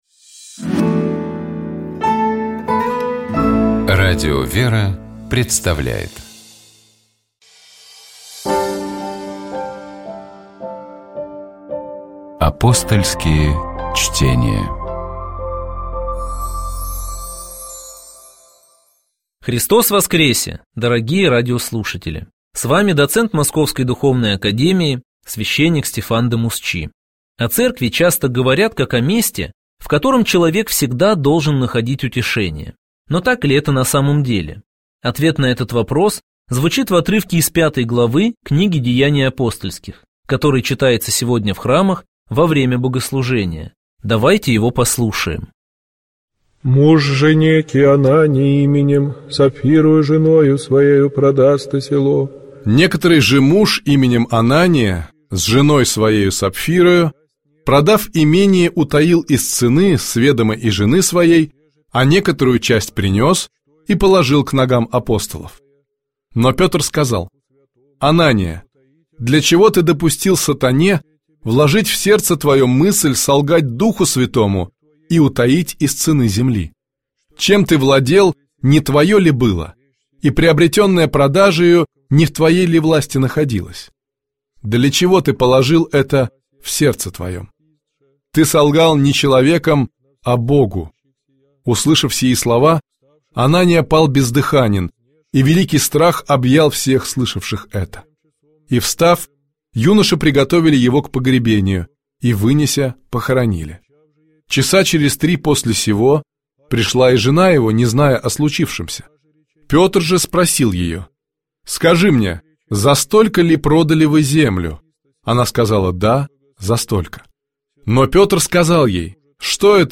Предлагаю вместе послушать тропарь Сергию Радонежскому полностью в исполнении сестёр Орского Иверского женского монастыря: